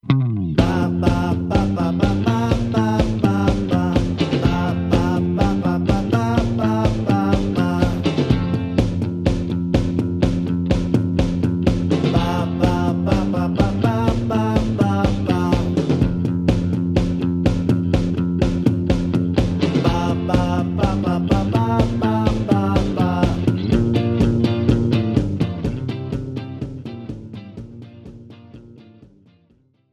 This is an instrumental backing track cover.
• Key – F
• With Backing Vocals
• With Fade